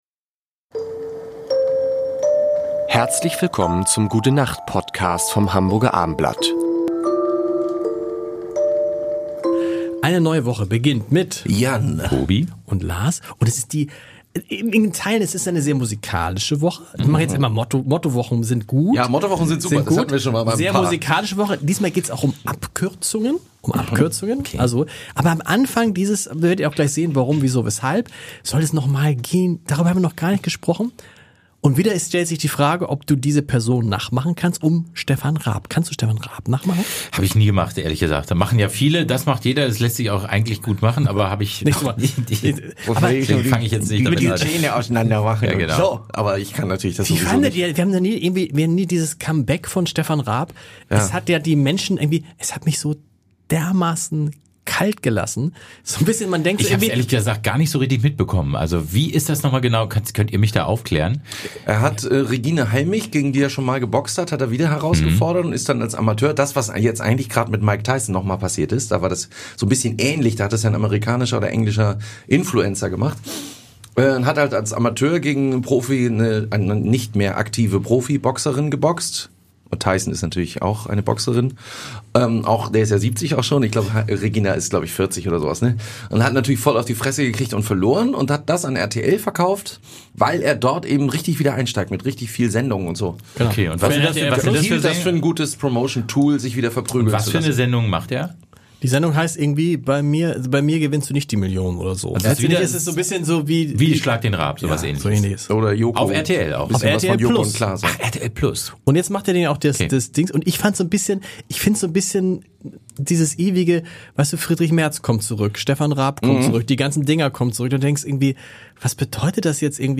Sie erwarten unterhaltsame, nachdenkliche und natürlich sehr musikalische fünf Minuten.